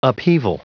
Prononciation du mot upheaval en anglais (fichier audio)
Prononciation du mot : upheaval